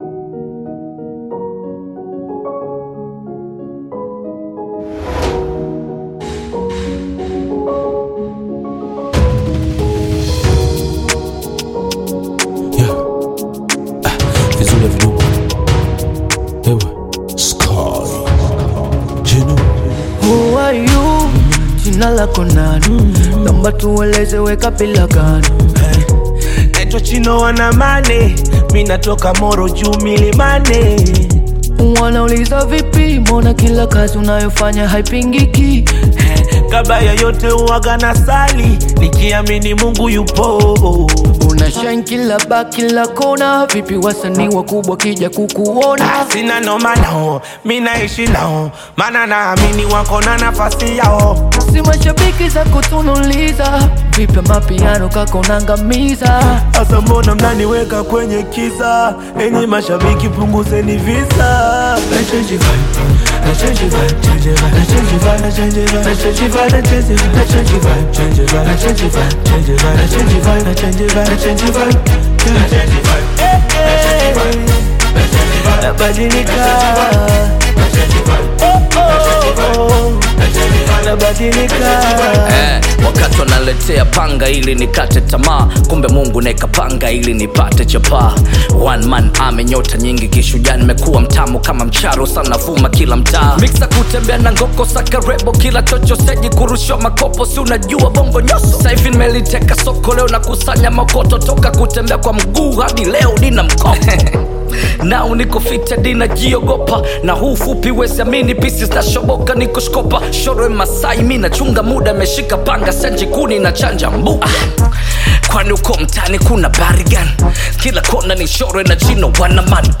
energetic new single